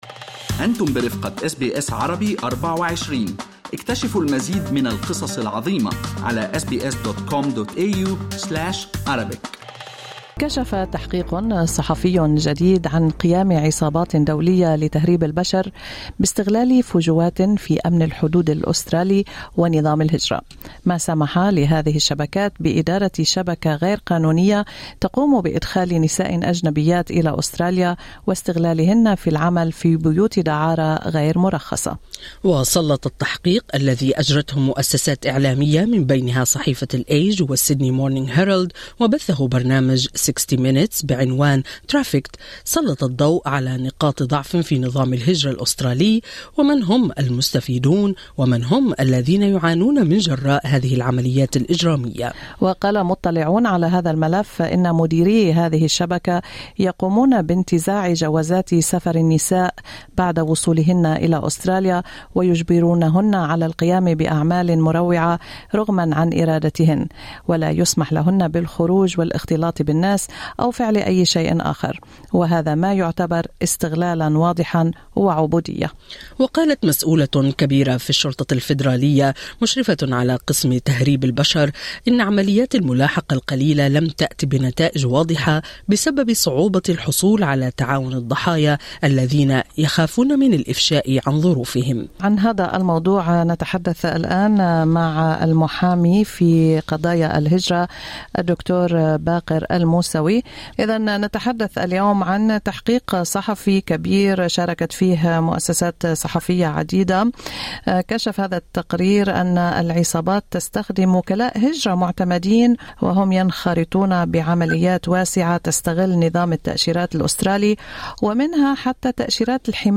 وكيل هجرة يفسر عمل القانون